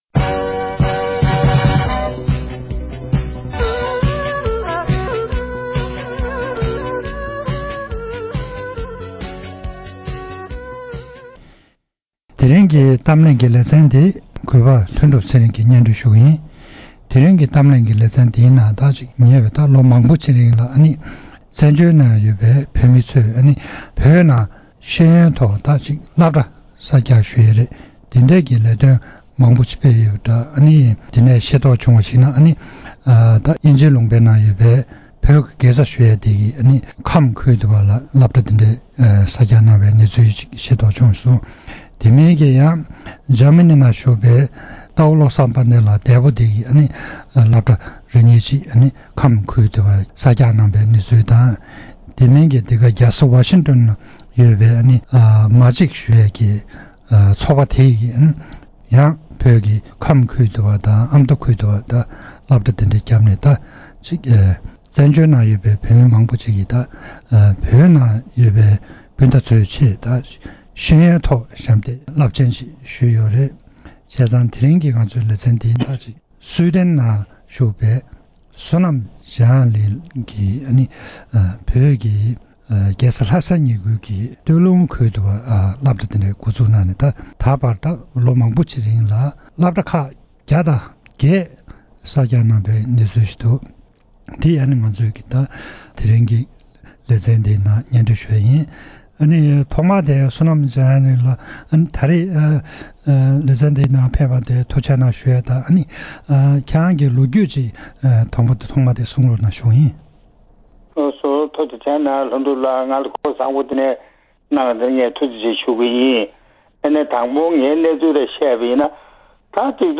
གཏམ་གླེང་ཞལ་པར་གྱི་ལེ་ཚན་ནང་།